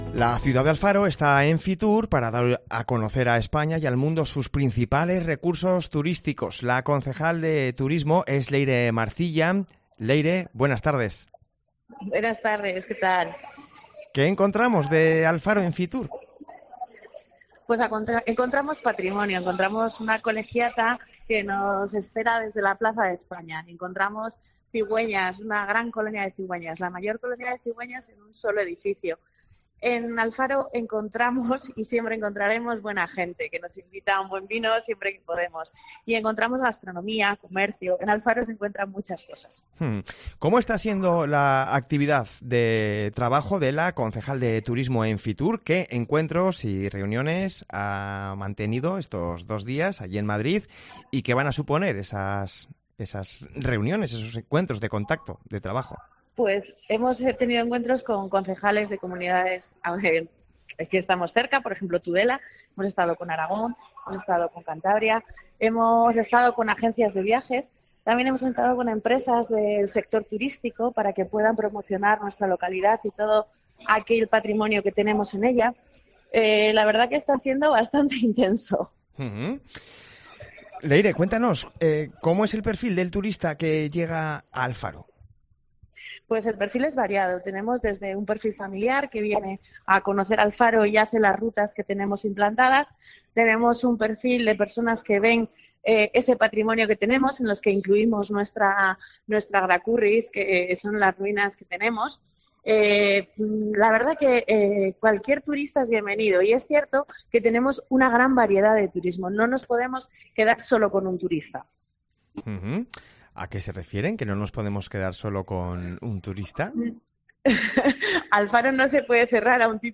Leyre Marcilla, concejal de Turismo, nos cuenta en COPE qué podremos ver durante esos días.